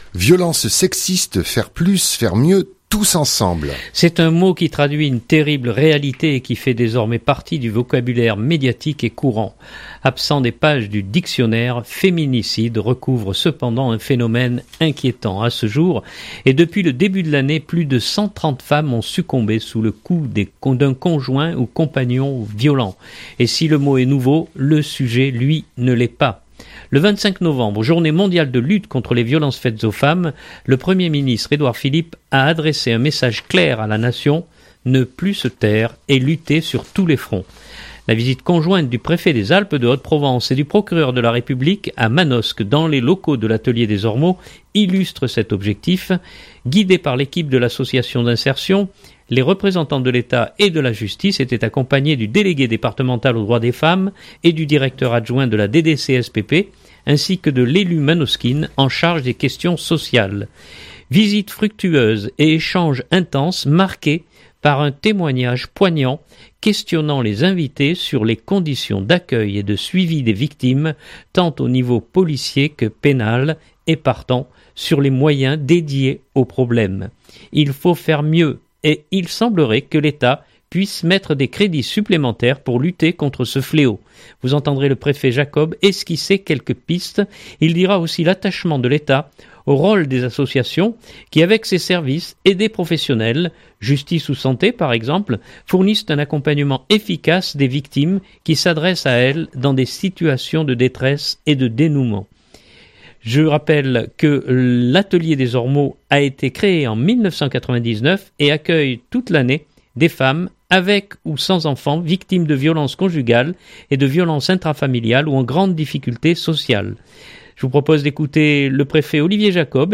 reportage violences sexistes.mp3 (5.88 Mo)
Vous entendrez le préfet Jacob esquisser quelques pistes. Il dira aussi l’attachement de l’Etat au rôle des associations qui, avec ses services et des professionnels (justice ou santé par exemple) fournissent un accompagnement efficace des victimes qui s’adressent à elles dans des situations de détresse et de dénuement.
Je vous propose d’écouter le préfet Olivier Jacob et le procureur de la république Stéphane Kellenberger à l’issue de leur visite manosquine.